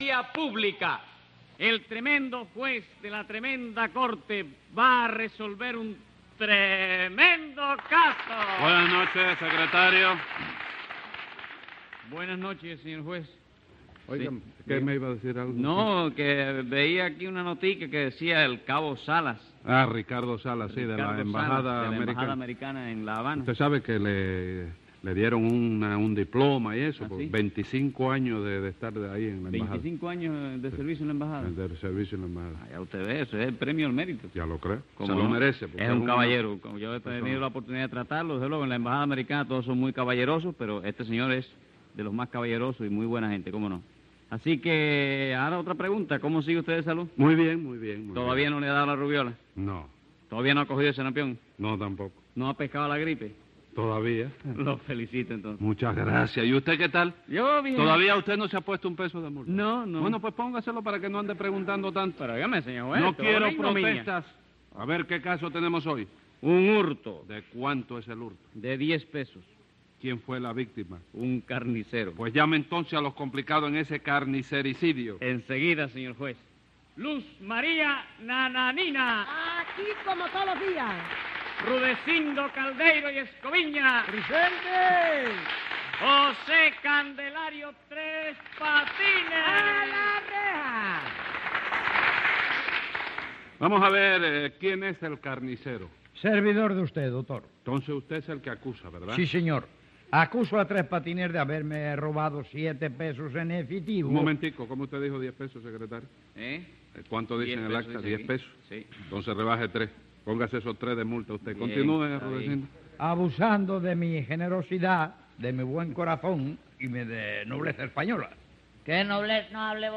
Divertidísimas situaciones en el más puro humor cubano en el legendario show de Tres Patines.